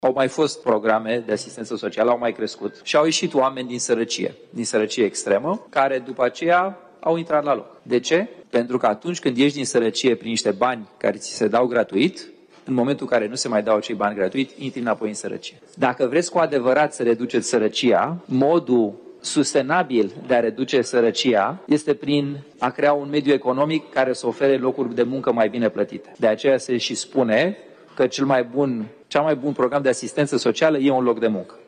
Continuă dezbaterile în comisiile reunite de buget–finanțe: Aleșii discută acum bugetul pentru Ministerul Muncii.
Deputatul USR, Claudiu Năsui: „Cel mai bun program de asistență socială e un loc de muncă”